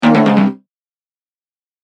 ボタン・システム （87件）
不正解2.mp3